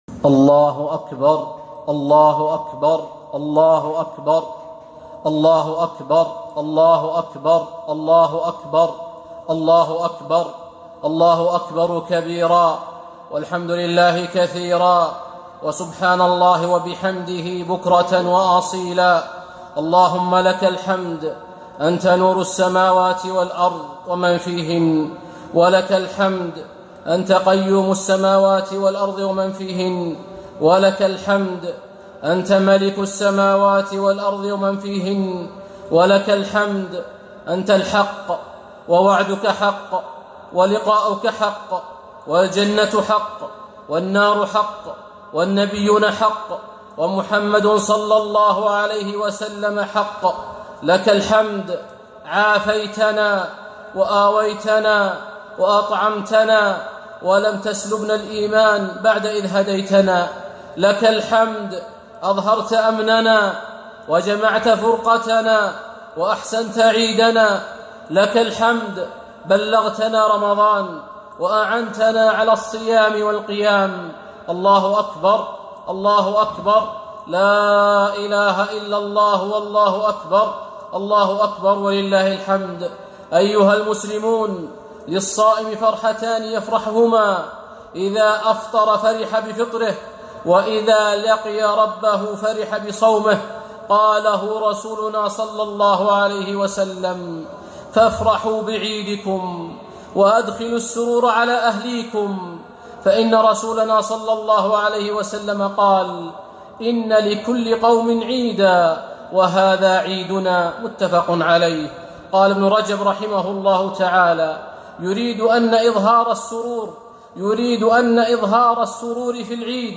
خطبة عيد الفطر المبارك 1442